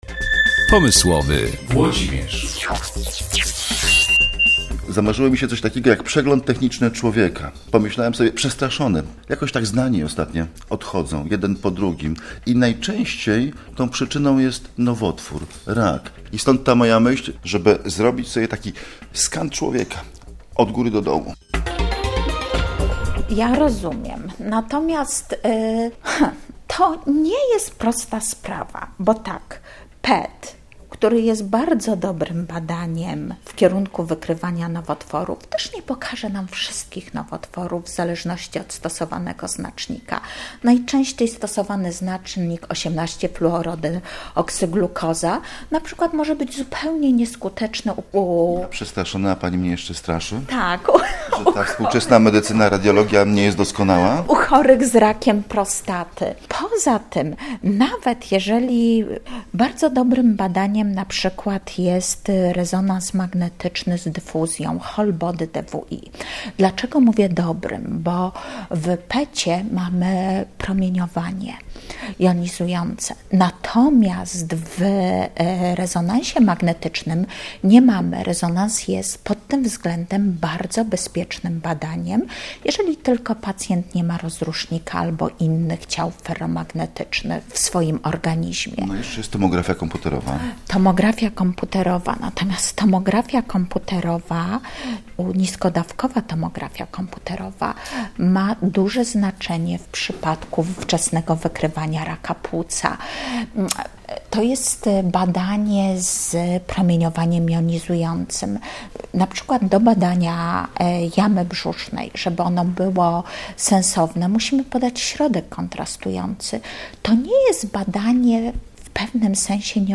POSŁUCHAJ ROZMOWY:/audio/dok2/pomyslowymol.mp3